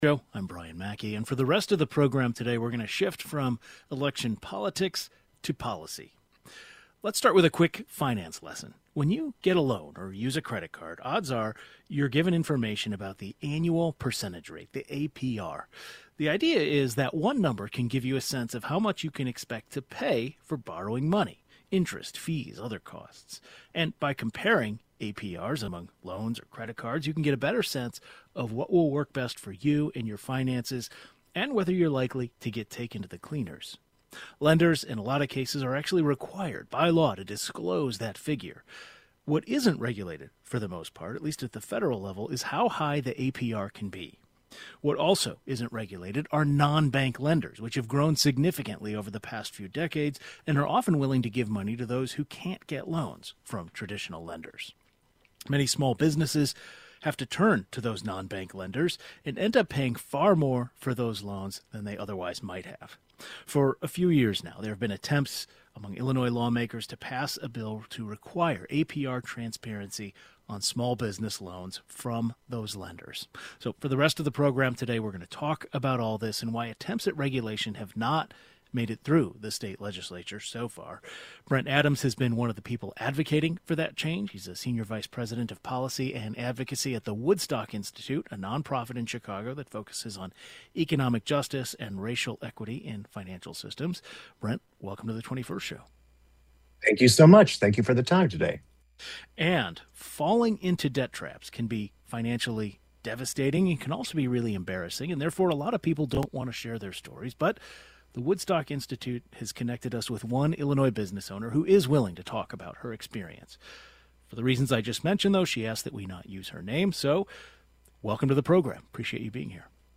An advocate for a bill to require APR transparency on small business loans from lenders joins the program today as well as an Illinois business owner, who became a victim of this practice.